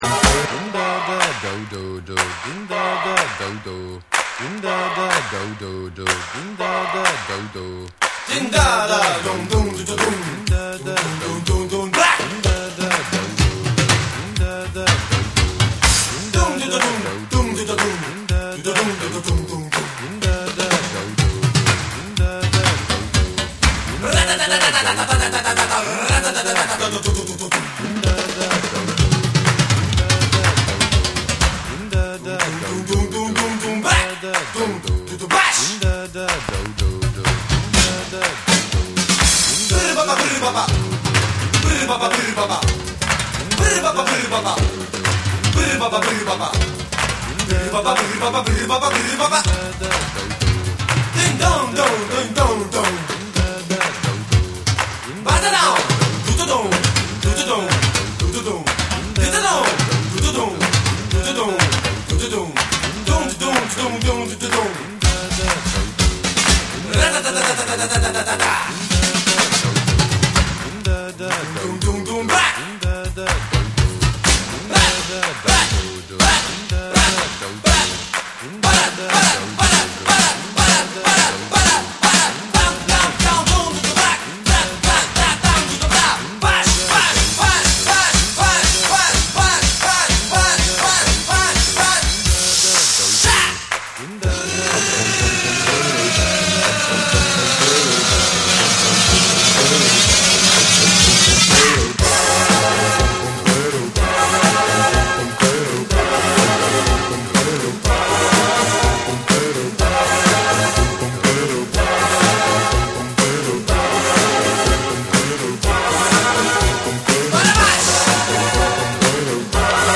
トライバルなビートに呪術的ともいえるスキャットが絡みスペイシーなシンセが展開する強烈ナンバー！
DANCE CLASSICS / DISCO